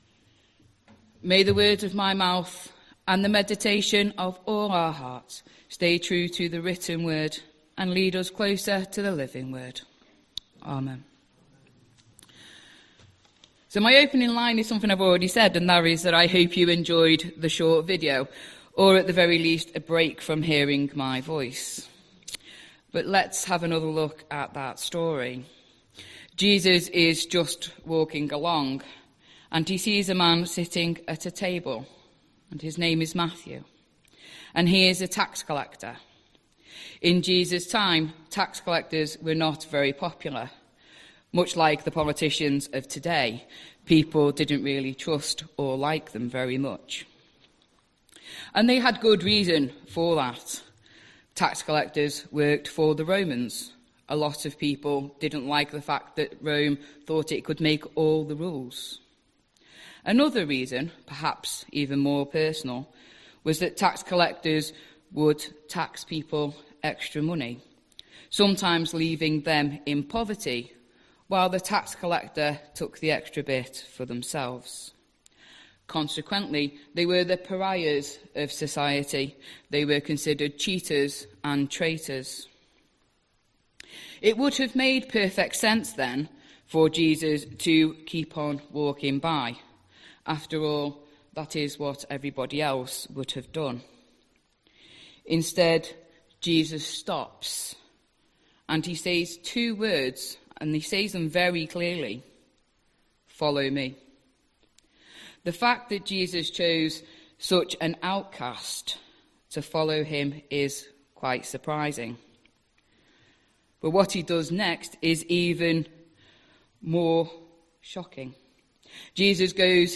5th October 2025 Sunday Reading and Talk - St Luke's